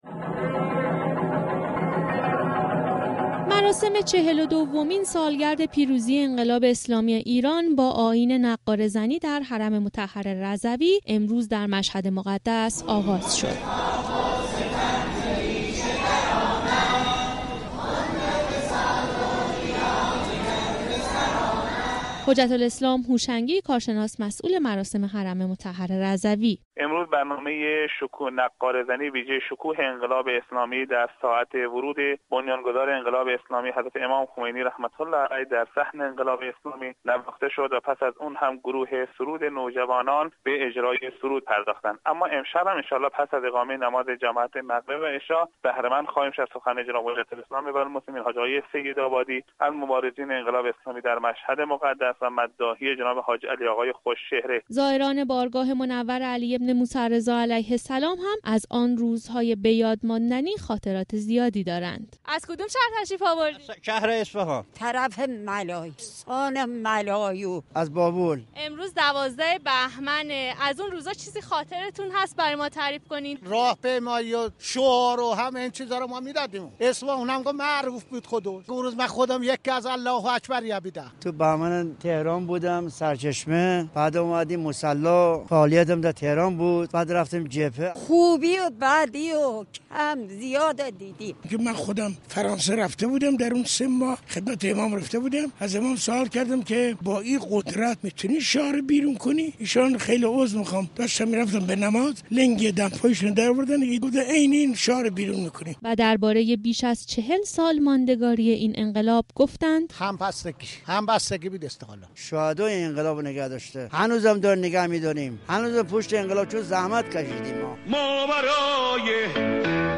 نقاره‌زنی در حرم مطهر رضوی به مناسبت لحظه ورود امام خمینی(ره) به ایران
در اولین روز از دهه فجر، به مناسبت لحظه ورود امام خمینی(ره) به ایران، نقاره‌ها در صحن و سرا‌های حرم مطهر رضوی به صدا درآمدند.
اجرای گروه سرود نوجوان در نخستین روز دهه فجر به مناسب ورود امام خمینی (ره) به وطن دیگر برنامه‌ای بود که امروز اجرا شد. گزارش خبرنگار رادیو زیارت رادر این زمینه بشنوید.